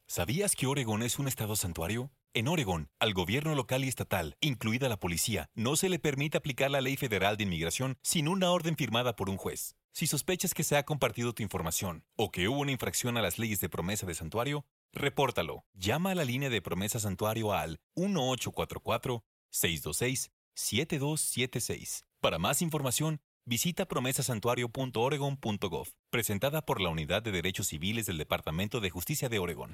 Spanish PSA Audio only - 30 sec